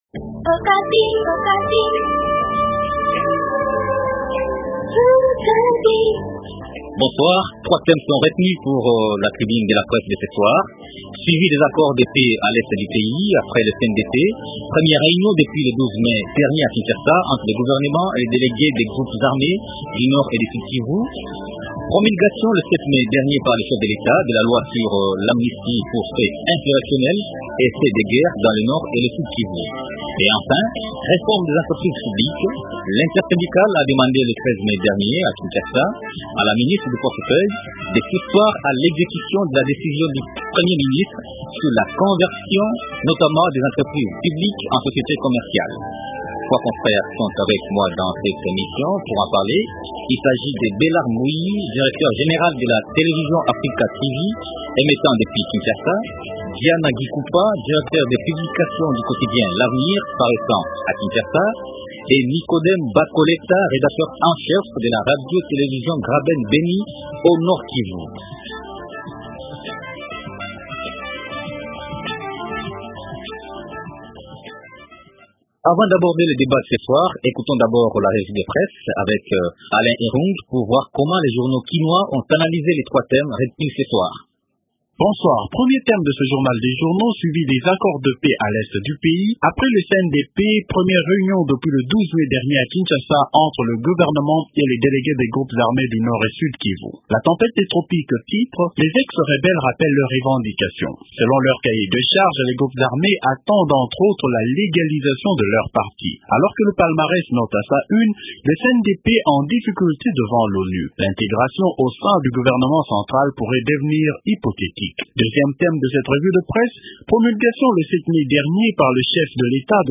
Trois thèmes au centre de la tribune de presse de ce soir, à savoir :